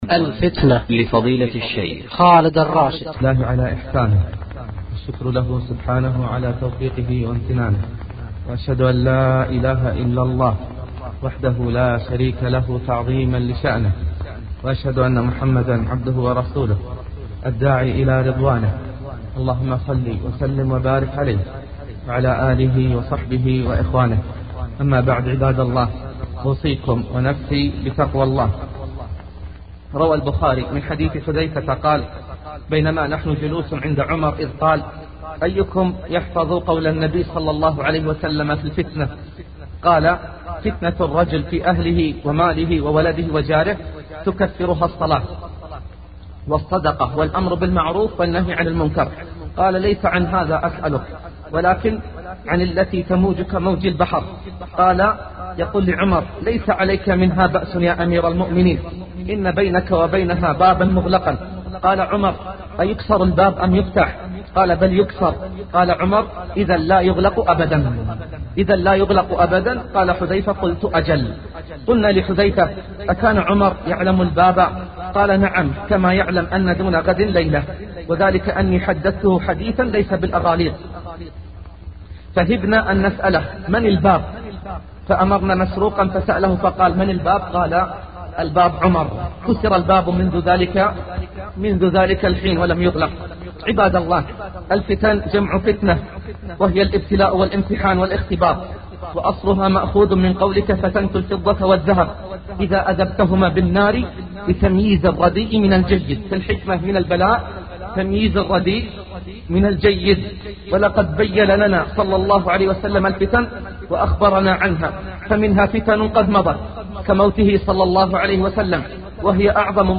للشيخ خالد الراشد
1. مقدمة الخطبة